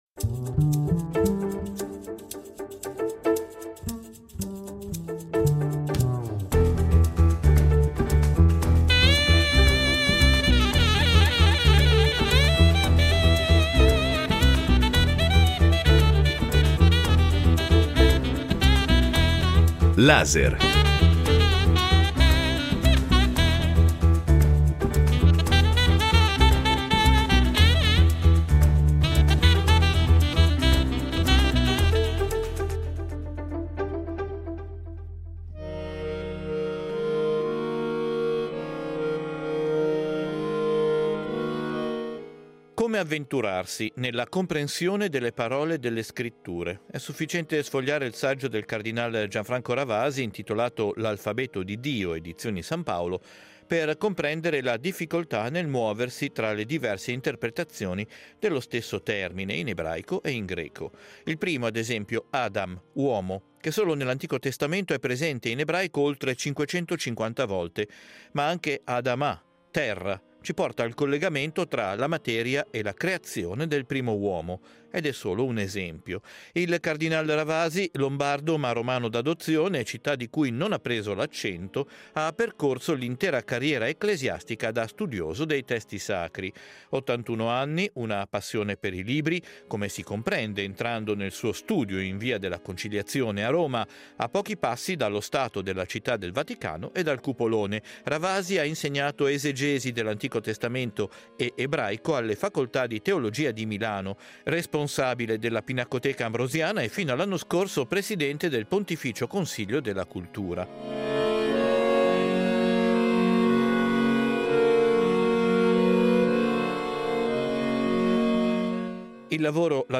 Incontro con il Card. Gianfranco Ravasi